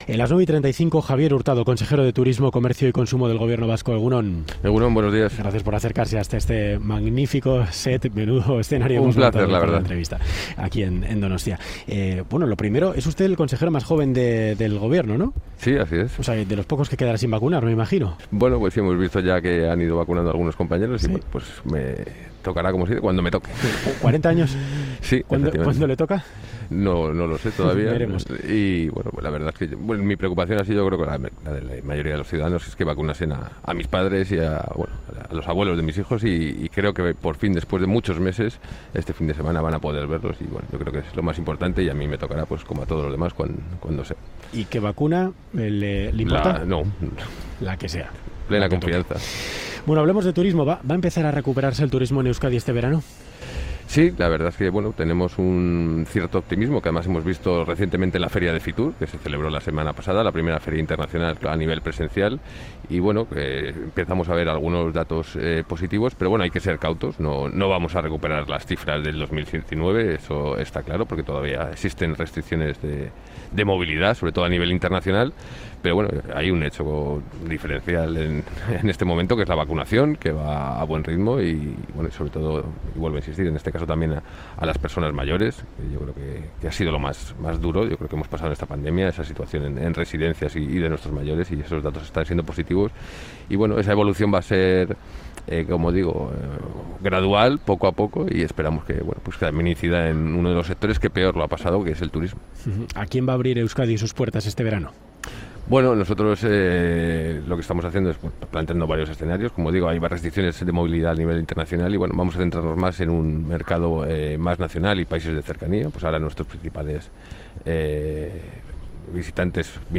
Audio: Con el aumento en la vacunación y la apertura de fronteras, Boulevard de Radio Euskadi sale a calle en busca de turistas.